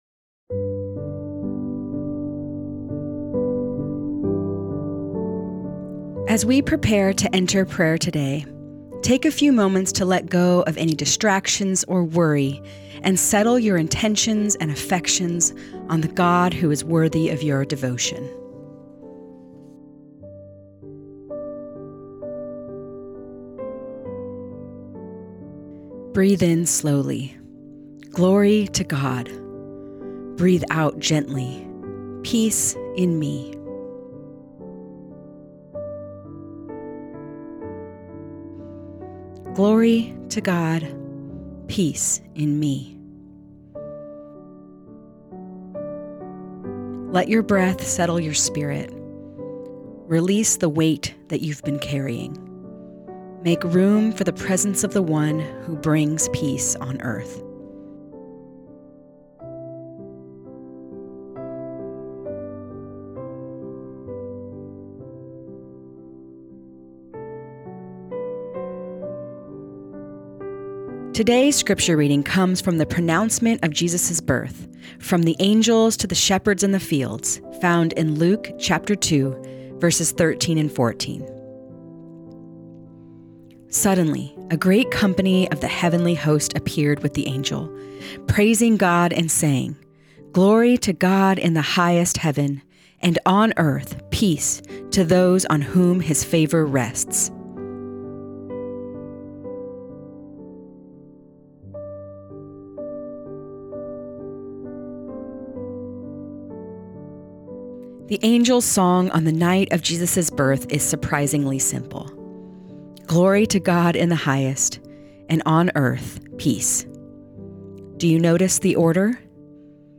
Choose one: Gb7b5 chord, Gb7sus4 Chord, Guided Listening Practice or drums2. Guided Listening Practice